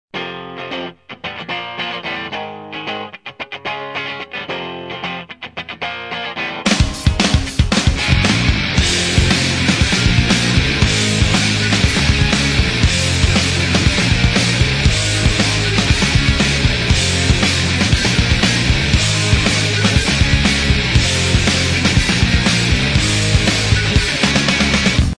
звучит эффектно , но немого жестковато .